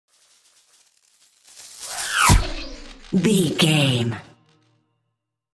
Whoosh sci fi disappear electricity
Sound Effects
Atonal
futuristic
tension